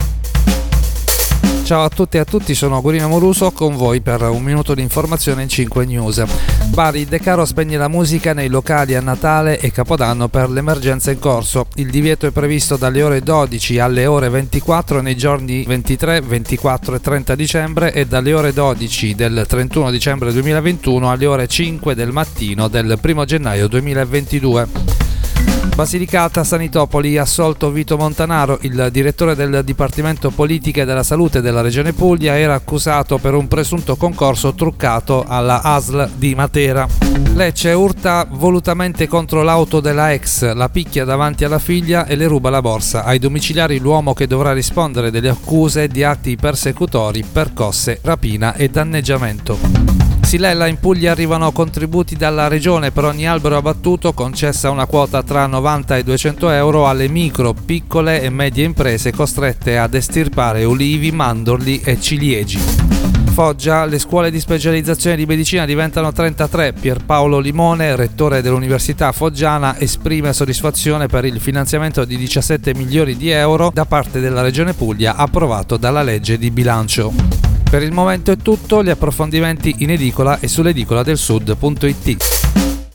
Giornale radio alle ore 19.